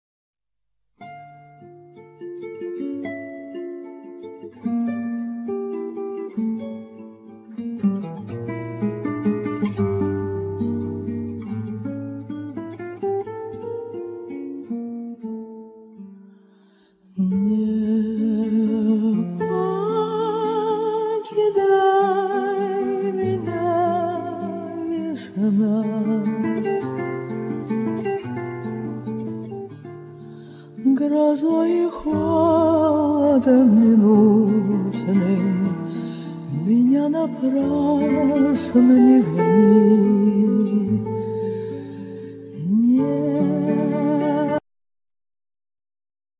Vocals
Piano,Keyboards,Vocals
Guitar,Percussions
Cello
Perussions
Flute